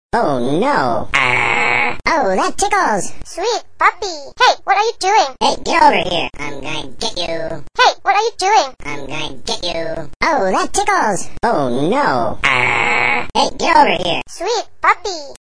It delivers a random assortment of phrases like "Hey, get over here!", "Oh, that tickles!", "I�m gonna get you!", "Oh no, Errrrrgh!", "Sweet puppy!" and "Hey, what are you doing?".